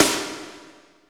53.05 SNR.wav